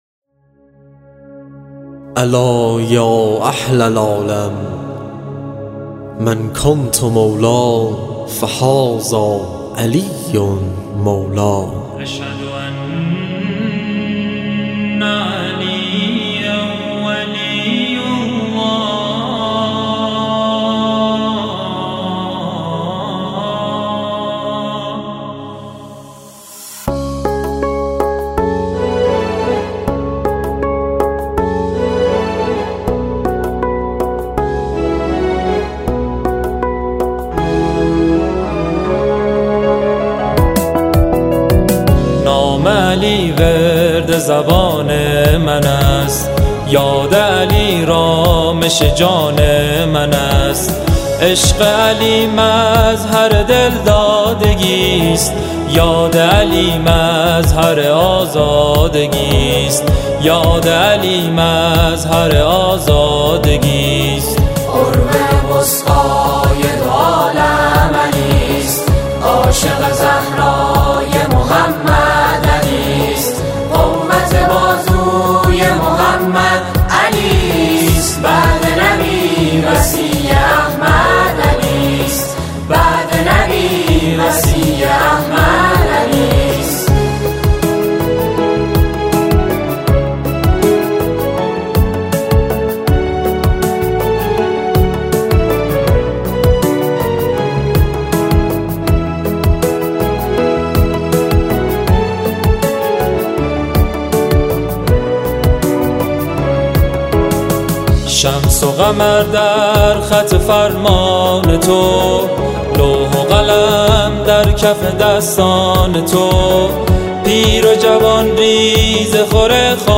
به صورت جمع خوانی